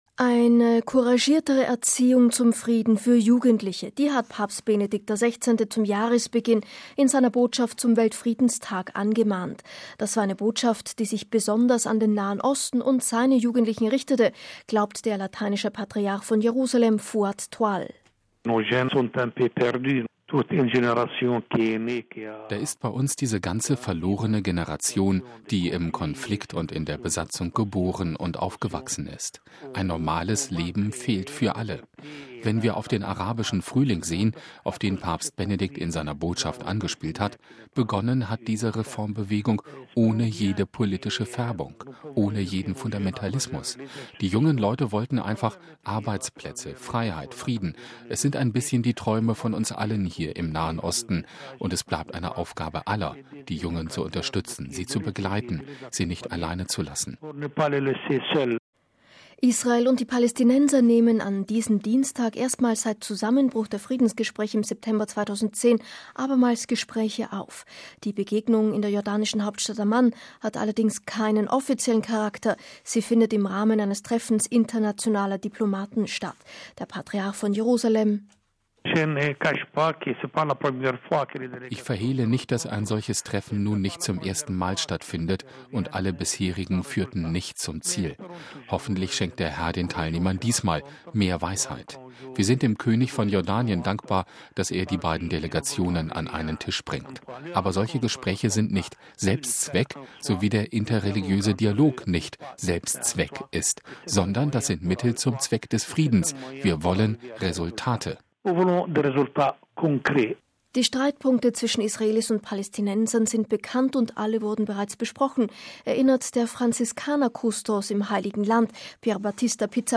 Die Streitpunkte zwischen Israelis und Palästinensern sind bekannt, und alle wurden bereits besprochen, erinnert der Franziskanerkustos im Heiligen Land, Pierbattista Pizzaballa im Gespräch mit uns: